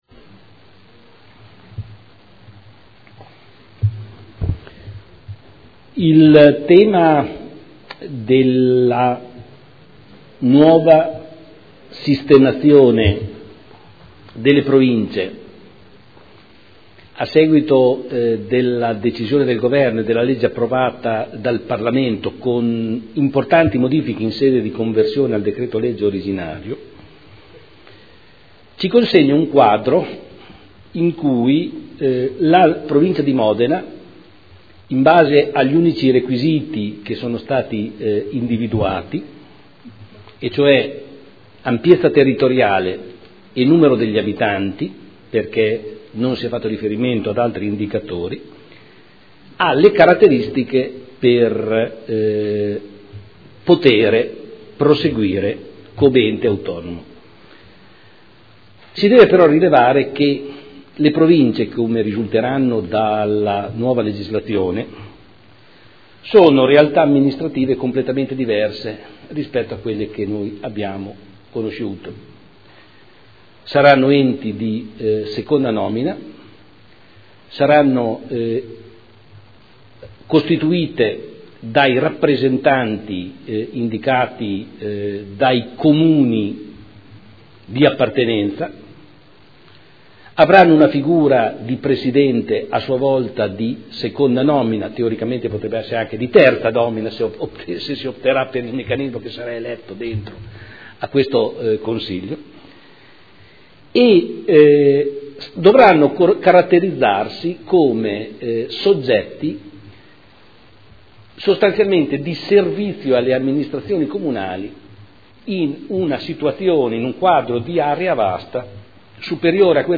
Seduta del 22/10/2012.